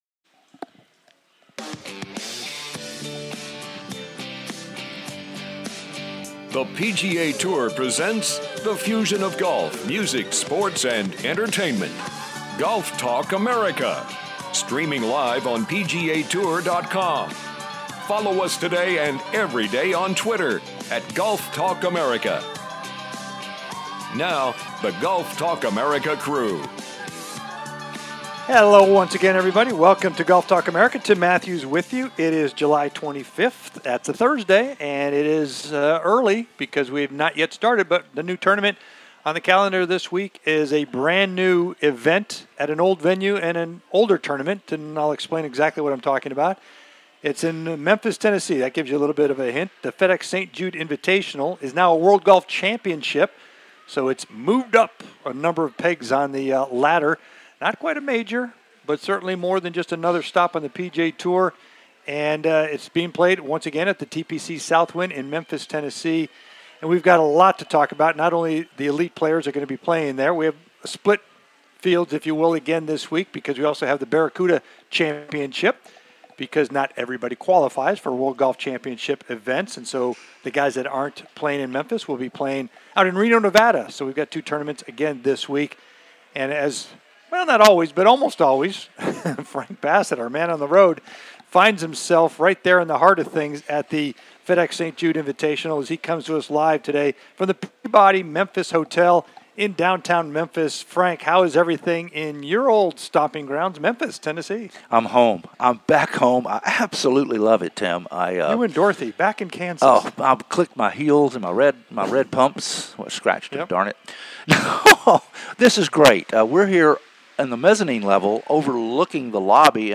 "LIVE" From Memphis Y'all... The WGC FedEx-St. Jude Invitational